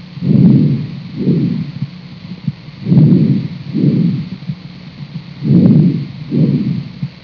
Click on the button to listen to what normal breathing sounds like when a doctor or nurse listens to normal breathing with a stethescope. When you are breathing normally, it takes about the same amount of time to breathe in (inspire) as it does to breathe out (expire).